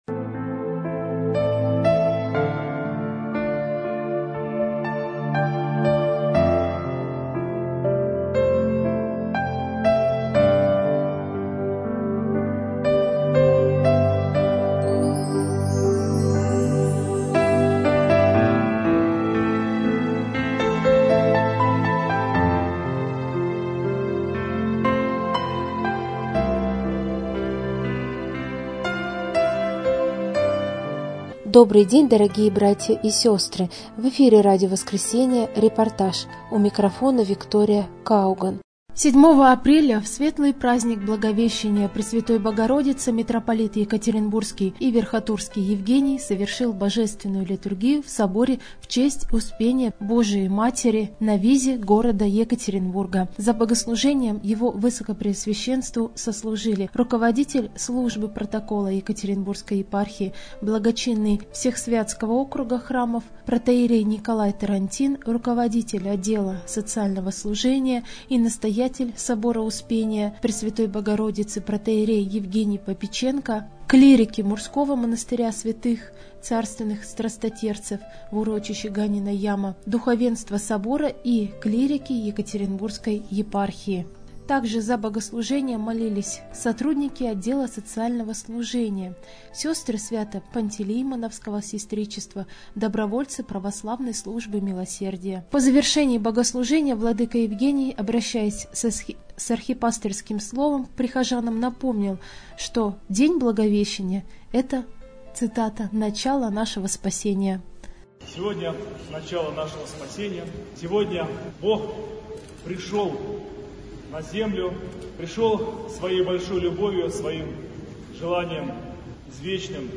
arhierejskaya_liturgiya_v_prazdnik_blagoveshcheniya_presvyatoj_bogorodicy_2025.mp3